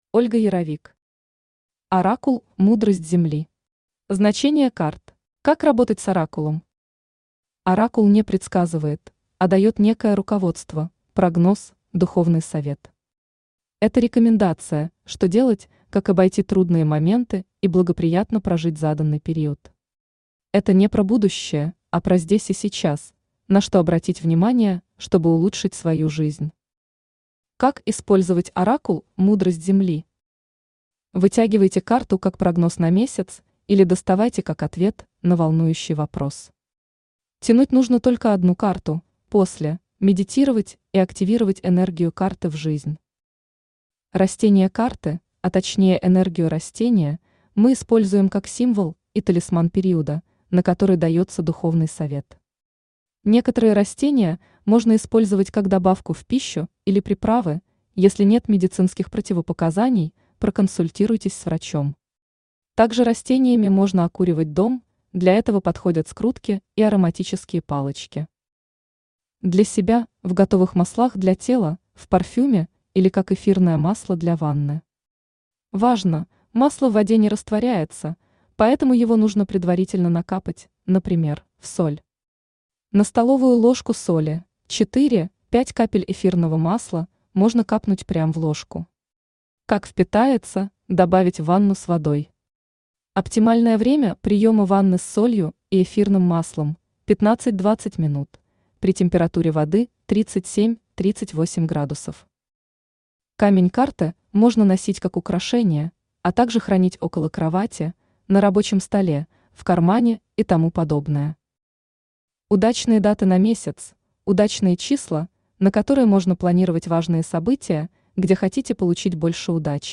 Значения карт Автор Ольга Яровик Читает аудиокнигу Авточтец ЛитРес. Прослушать и бесплатно скачать фрагмент аудиокниги